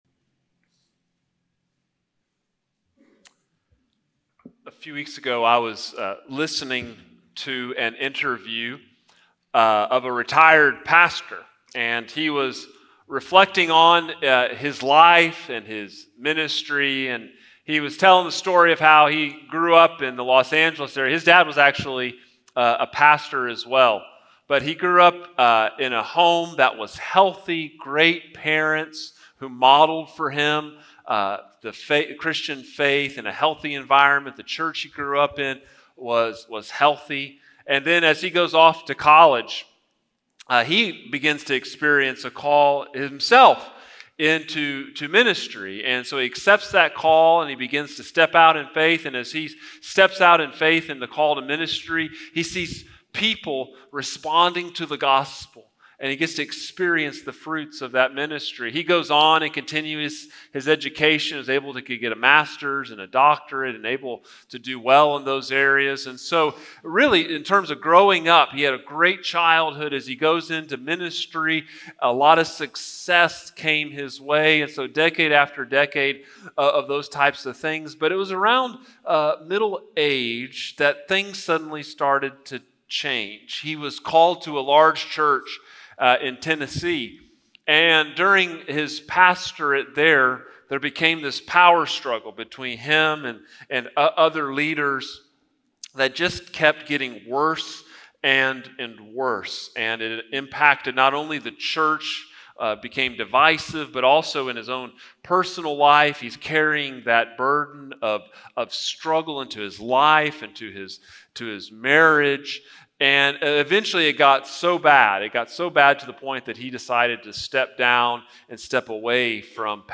Sermons | First Baptist Church, Brenham, Texas